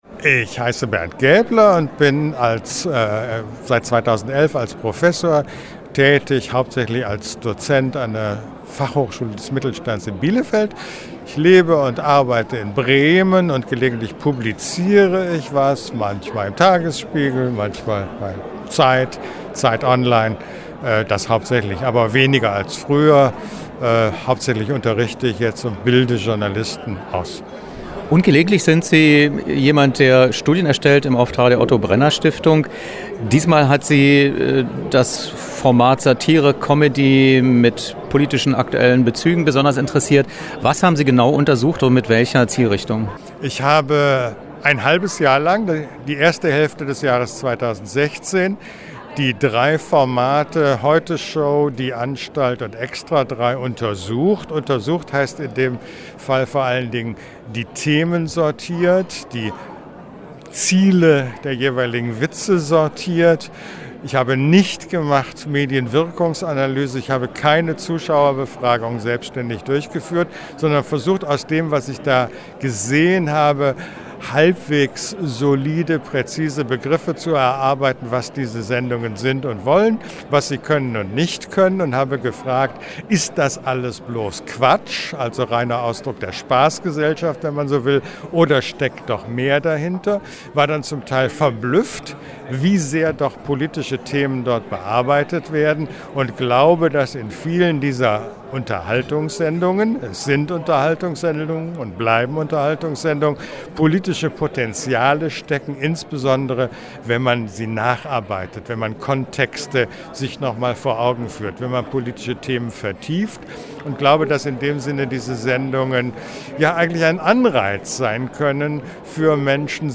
Was: Interview zur Studie: „Quatsch oder Aufklärung?“ – Analyse von TV-Satiresendungen
Wo: Pullman Berlin Schweizerhof, Budapester Str. 25, 10787 Berlin